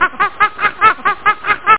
MONKEY.mp3